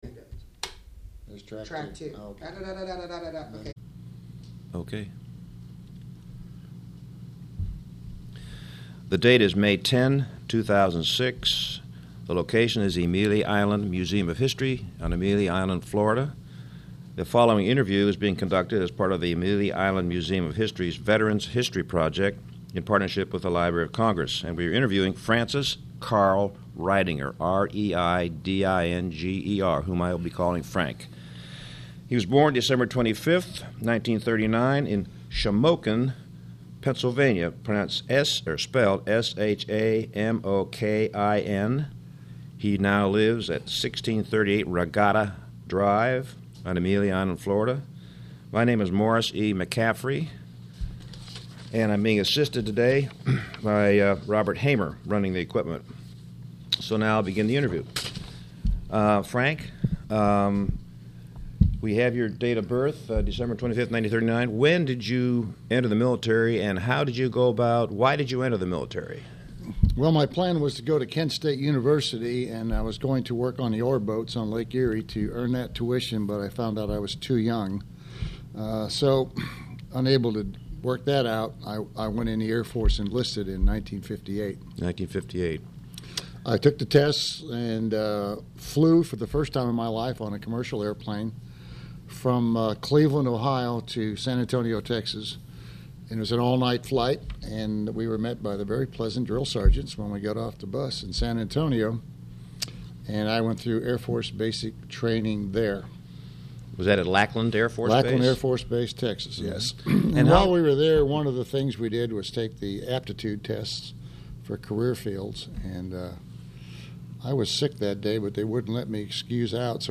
Click Here to play the Oral History Recording.